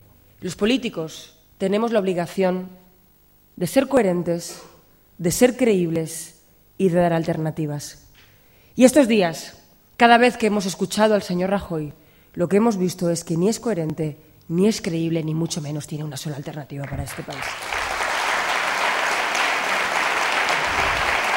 Cortes de audio de la rueda de prensa
LEIRE_PAJiN_GUADALAJARA1.mp3